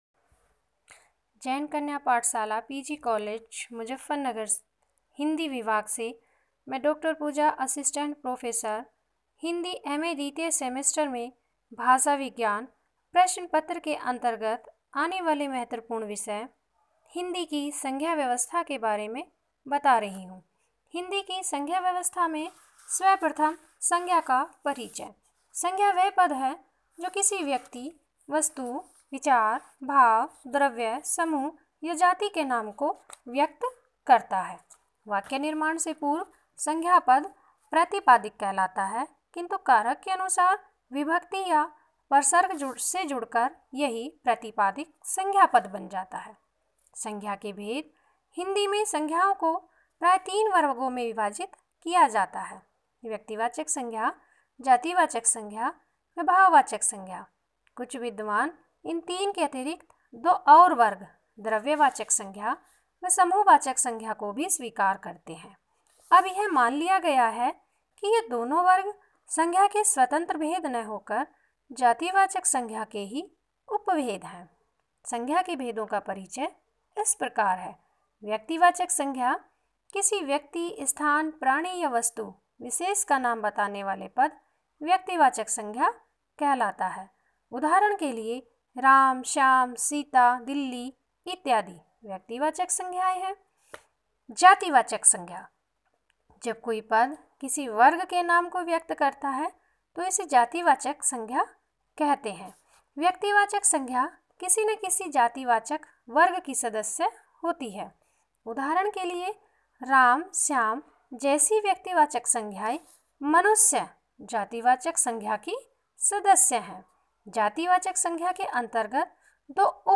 Audio Lectures